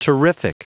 Prononciation du mot terrific en anglais (fichier audio)
Prononciation du mot : terrific